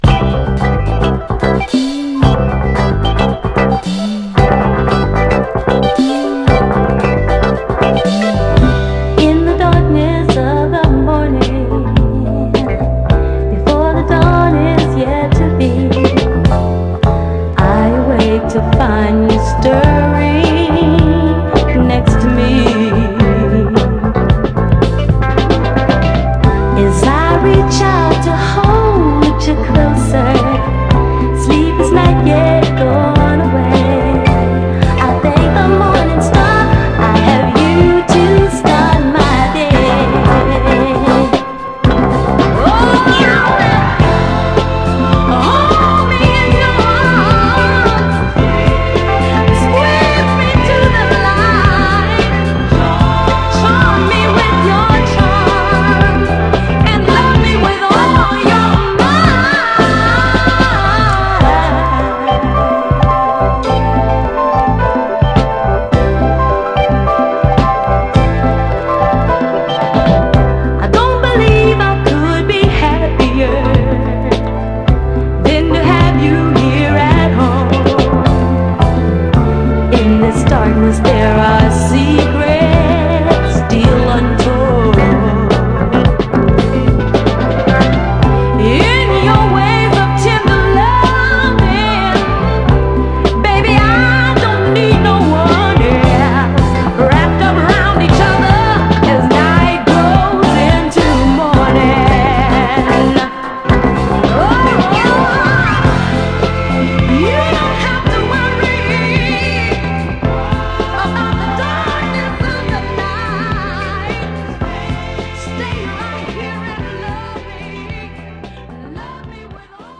メロウでかつ伸びやかなヴォーカルに高揚感溢れるフックもたまらない、爽快モダン・ダンサー
高揚感が加速する終盤の展開も素晴らしいです。
※試聴音源は実際にお送りする商品から録音したものです※